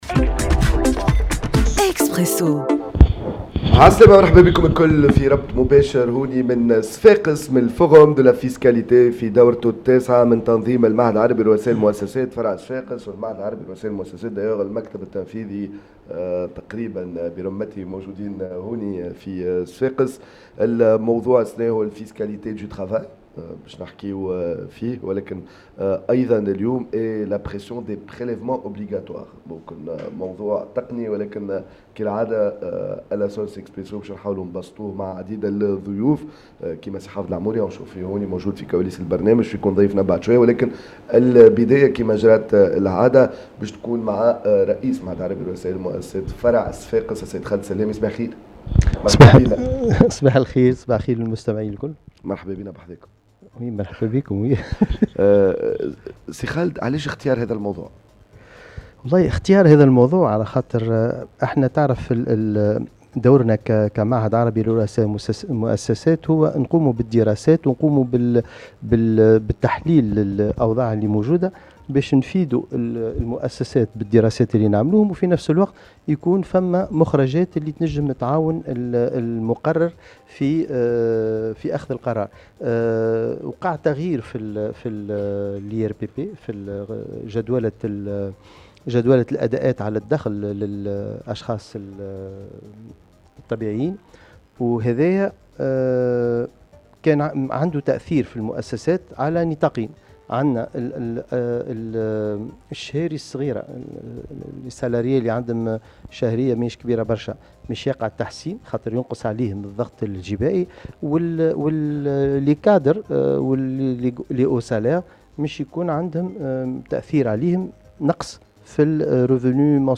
اكسبرسو مباشرة من منتدى الجباية في نسخته التاسعة